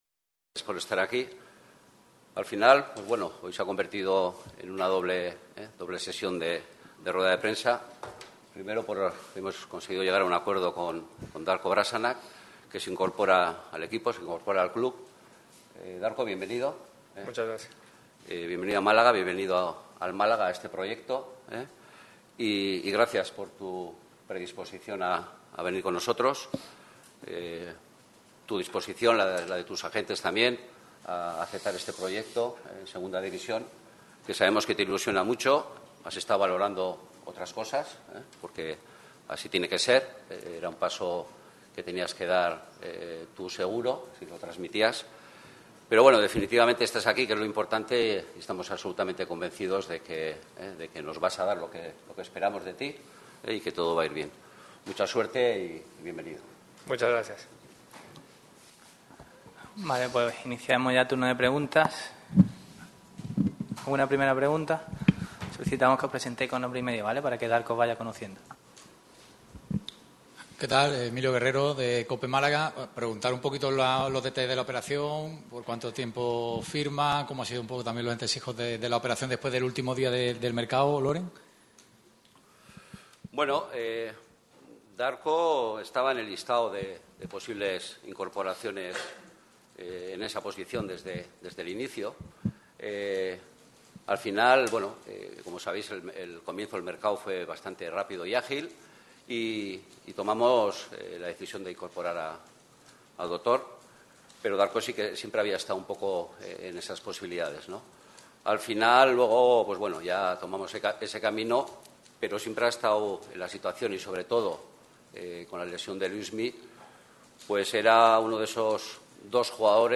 Aquí la comparecencia del centrocampista serbio.